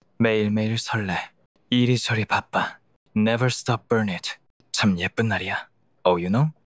跨语种复刻也可以办到，智东西让Speech 2.5用热血韩漫男主的音色说“美美桑内”歌词，在韩语和英语中切换：